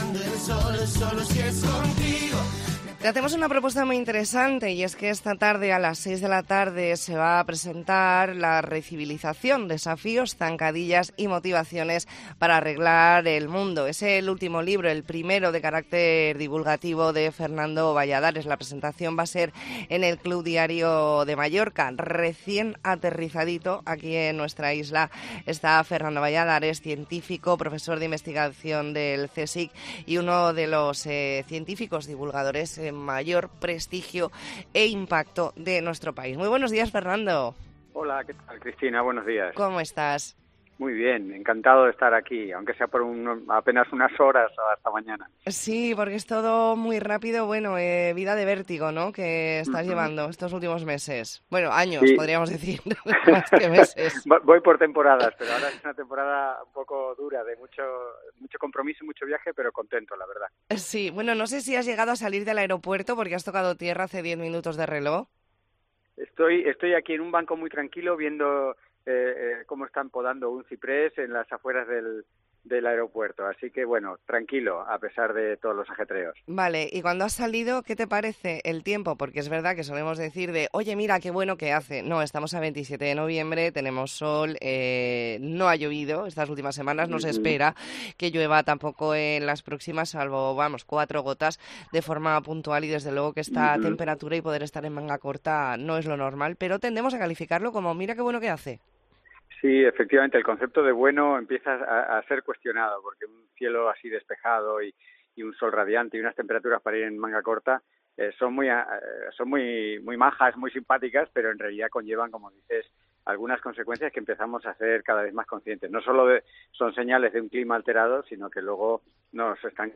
Entrevista en La Mañana en COPE Más Mallorca, lunes 27 de noviembre de 2023.